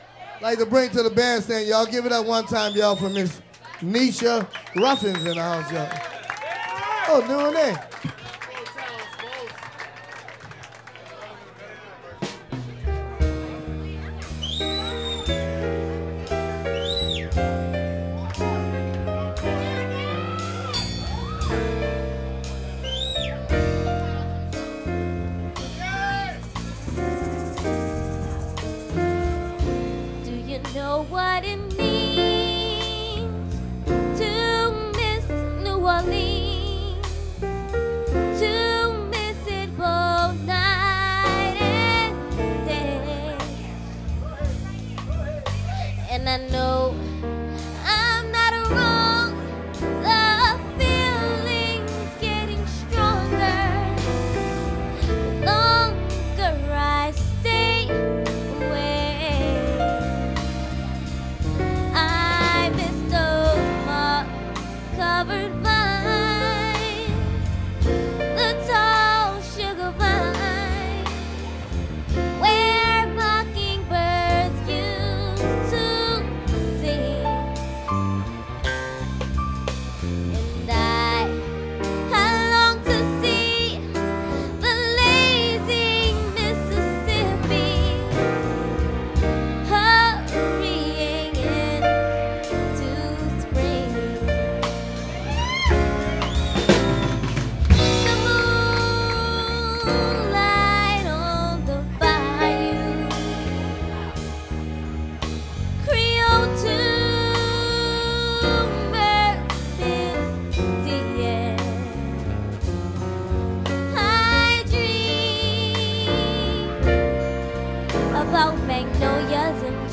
recorded live
at that restaurant